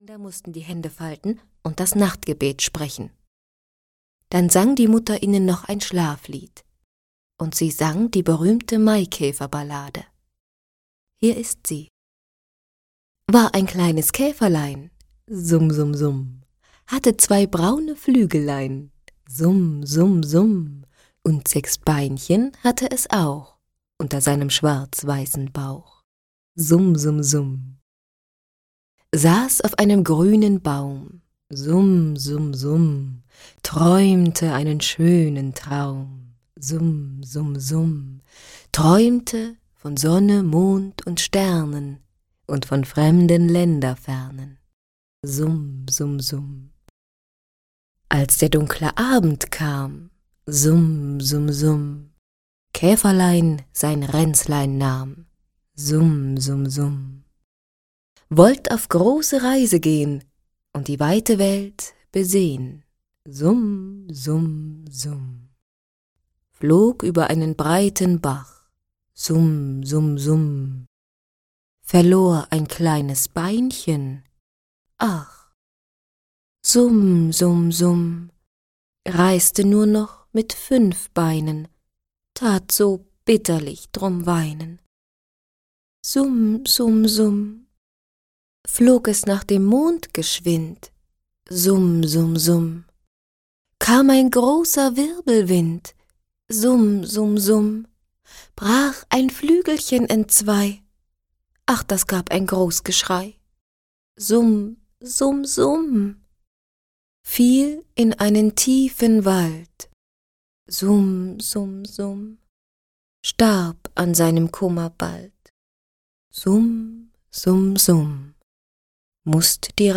Hörbuch Peterchens Mondfahrt, Gerdt von Bassewitz.